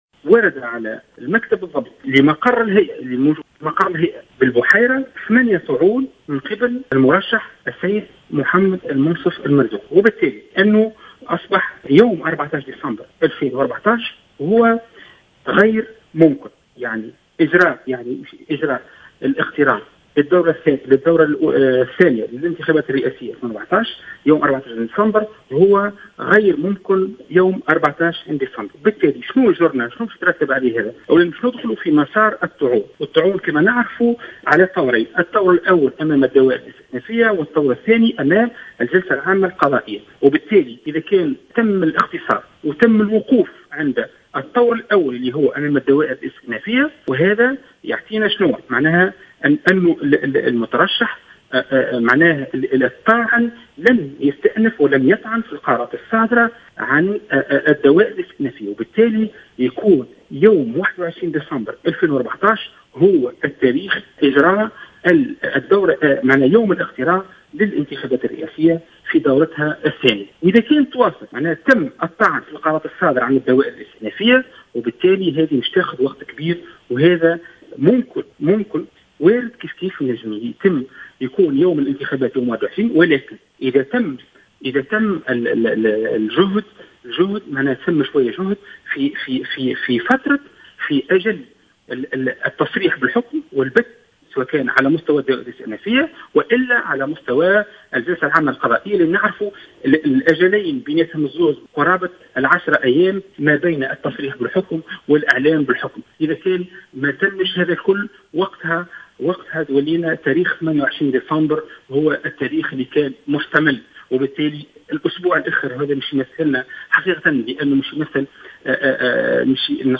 افاد عضو الهيئة العليا المستقلة للانتخابات انور بن حسن في تصريح لجوهرة "اف ام" اليوم الجمعة 28 نوفمبر 2014 ان المترشح للانتخابات الرئاسية المنصف المرزوقي تقدم ب 8 طعون في النتائج الاولية للرئاسية.